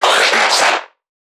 NPC_Creatures_Vocalisations_Infected [98].wav